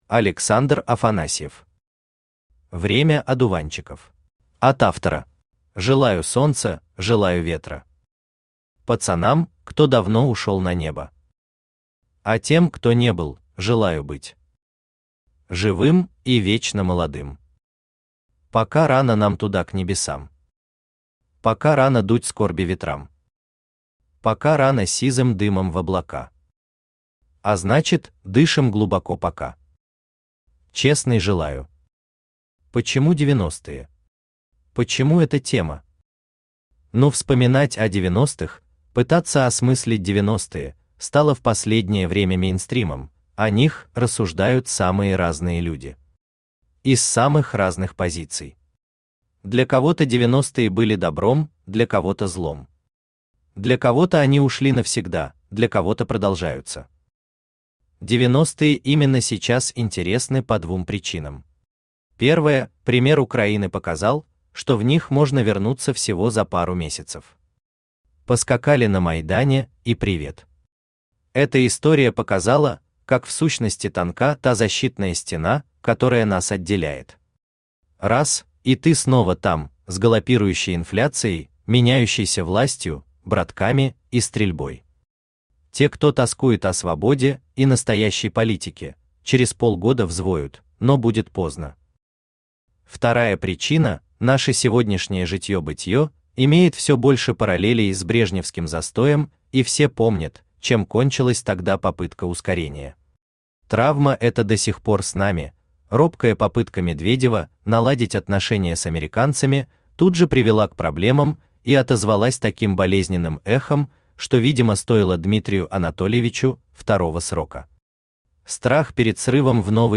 Аудиокнига Время одуванчиков | Библиотека аудиокниг
Aудиокнига Время одуванчиков Автор Александр Афанасьев Читает аудиокнигу Авточтец ЛитРес.